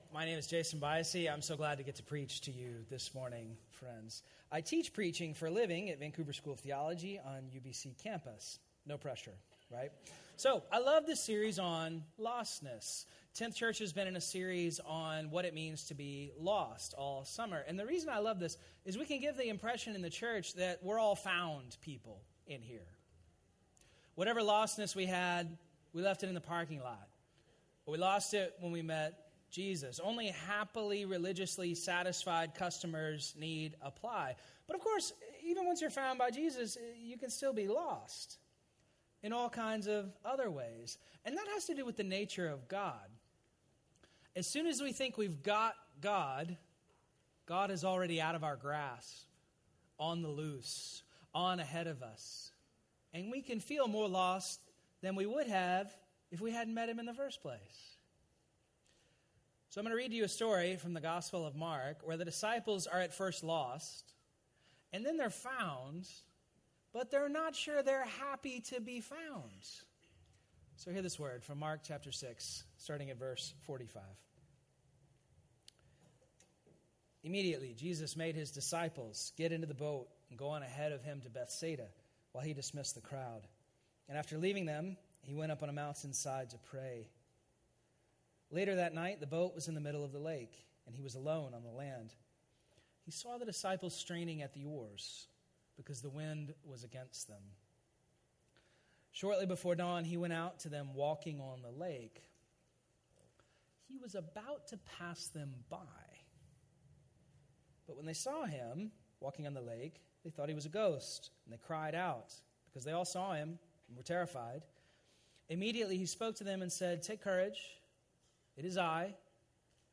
Episode from Tenth Church Sermons